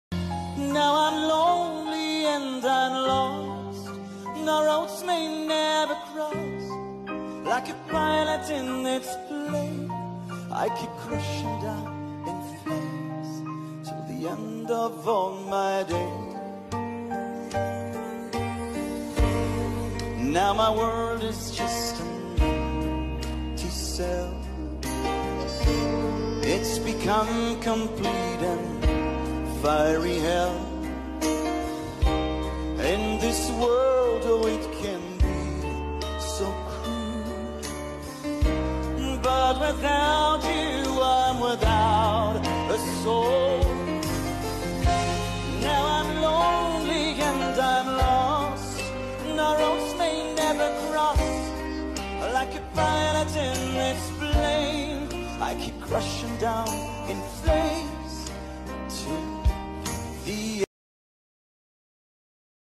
his voice is like the voice of an angel